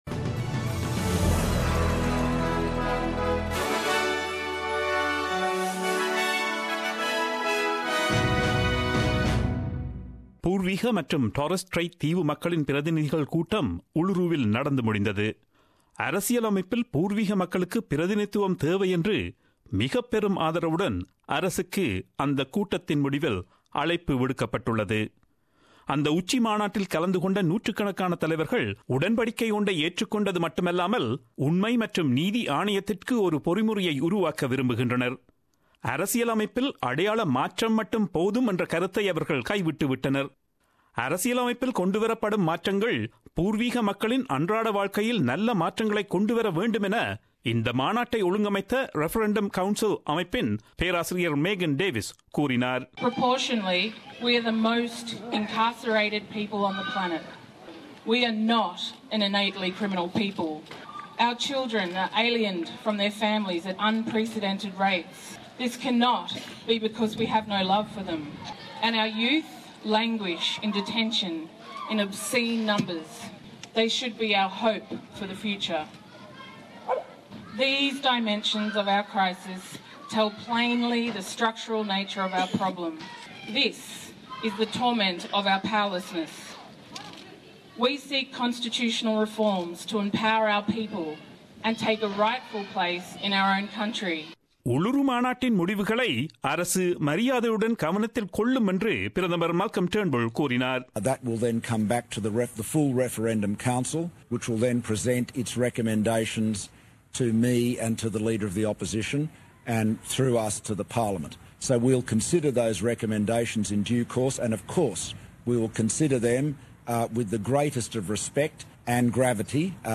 Australian news bulletin aired on Friday 26 May 2017 at 8pm.